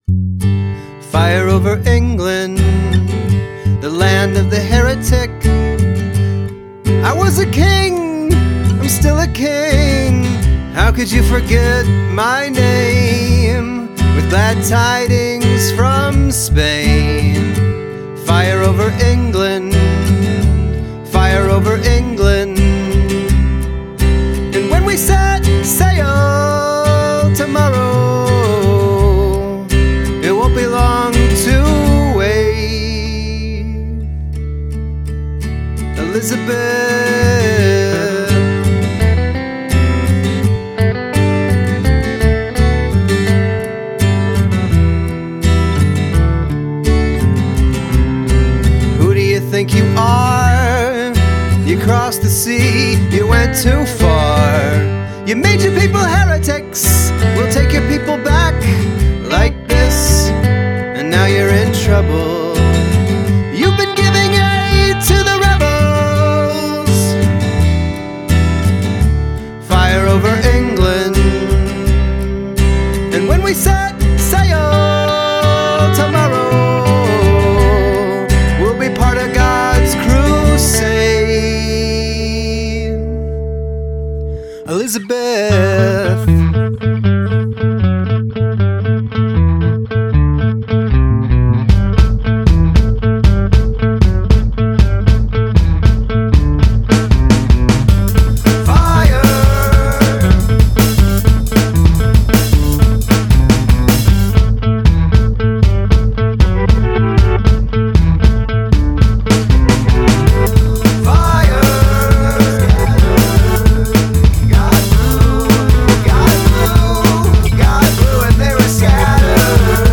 Dramatic Change in Tempo